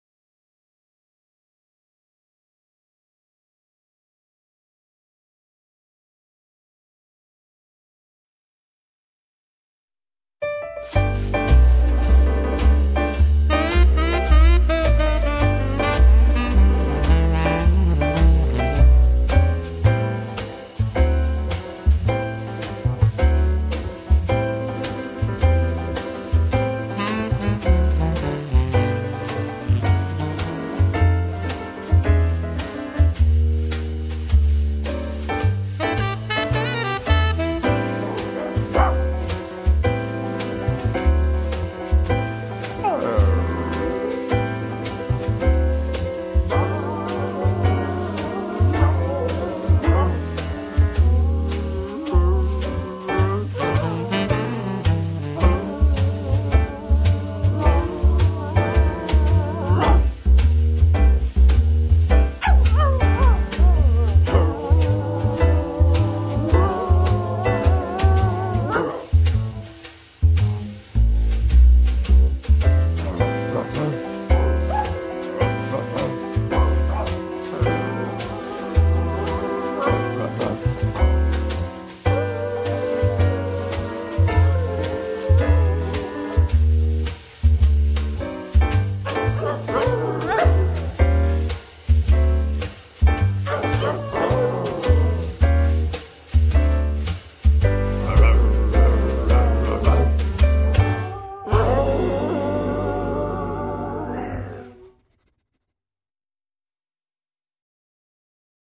Битрейт аудио: CBR / Stereo / 44.1 kHz / 128 kbps
Instrumental Version